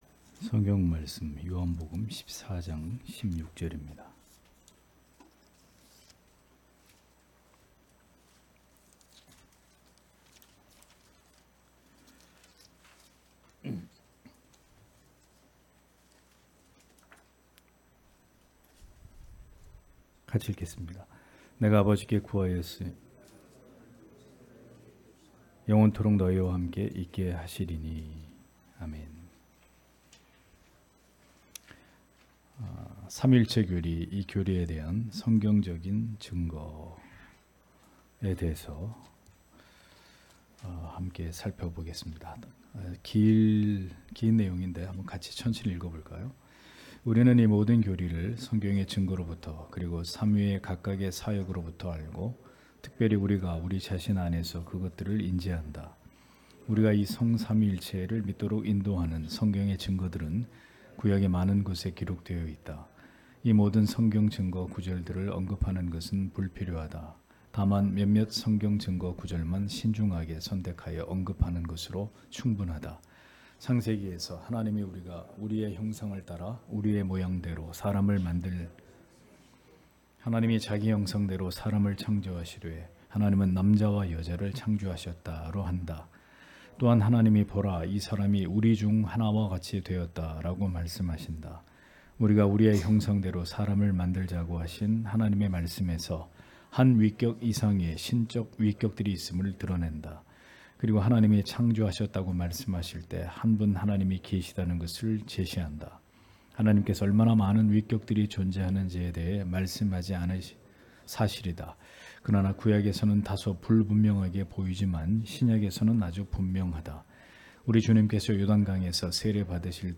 주일오후예배 - [벨직 신앙고백서 해설 9] 제9항 이 교리에 대한 성경적인 증거 (요14:16)